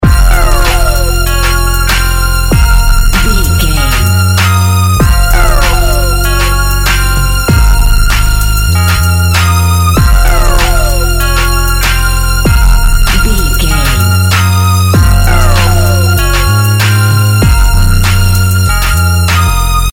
Aeolian/Minor
electronic
techno
trance
industrial
glitch
synth drums
synth leads
synth bass